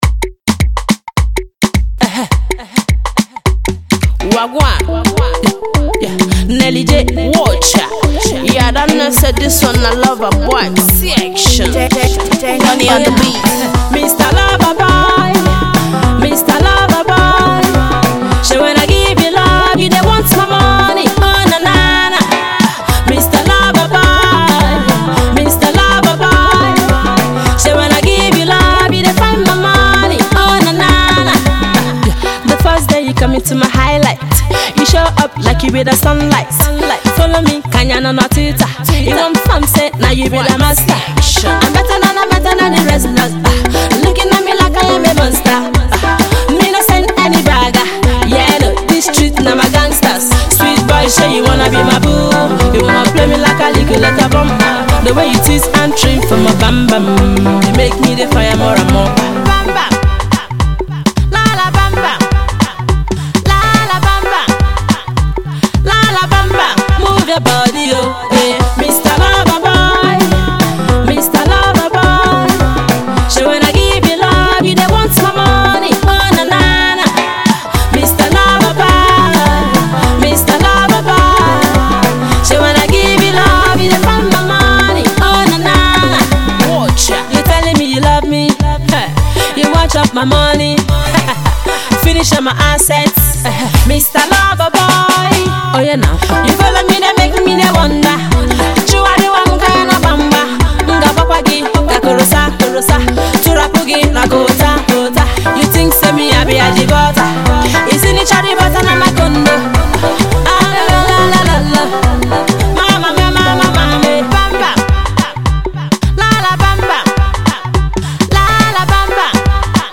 Pop
Dancehall/Galala Elements